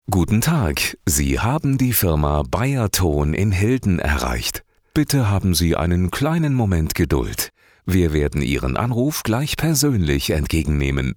Bei der Produktion von Sprachaufnahmen arbeitet das BEYERTONE Studio ausschließlich mit professionellen Sprecherinnen und Sprechern aus den Bereichen Rundfunk und Fernsehen zusammen, um einen gleichbleibend freundlichen Ton garantieren zu können.
kräftig, dynamisch